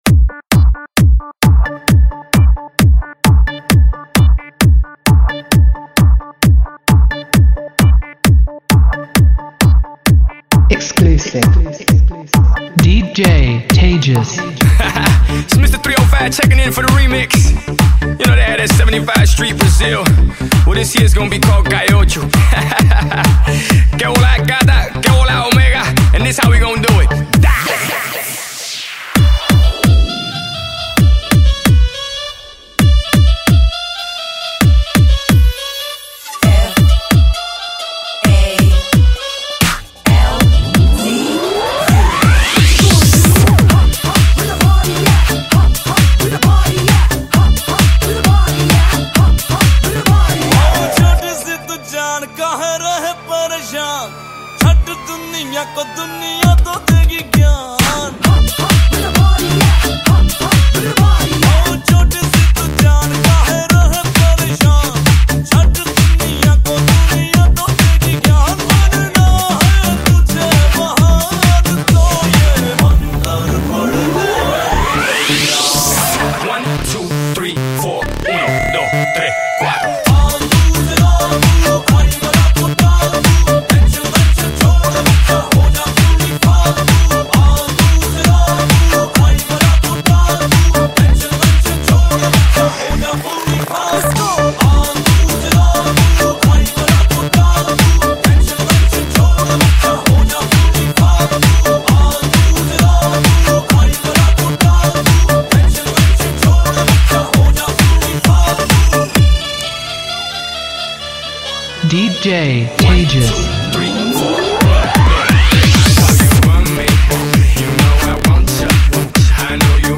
Category : Hindi Remix Song